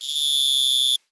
cricket_chirping_solo_04.wav